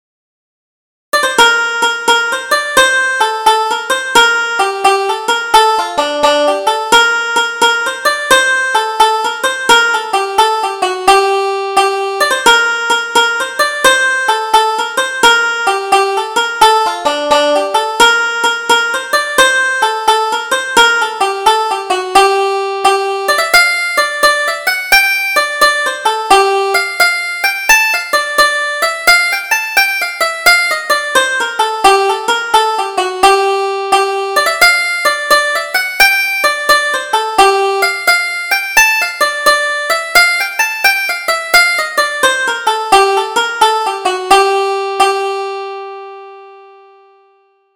Double Jig: The Bunch of Roses